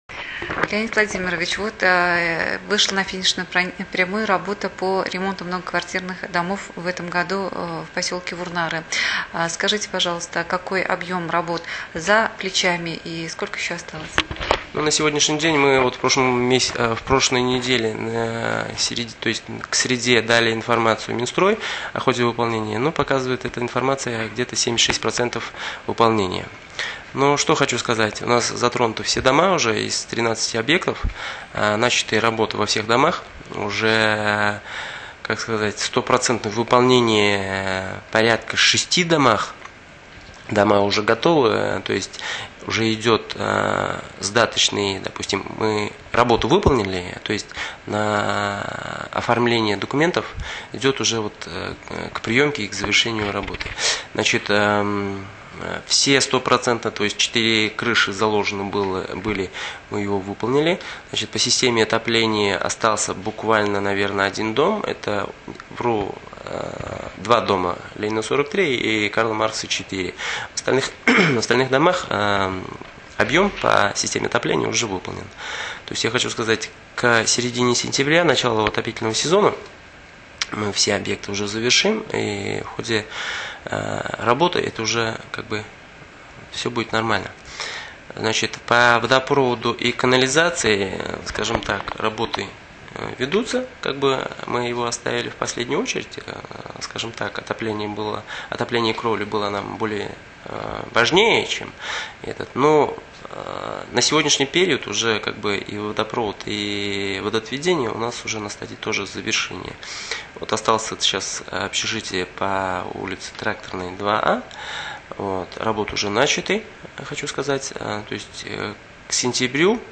аудиозапись интервью):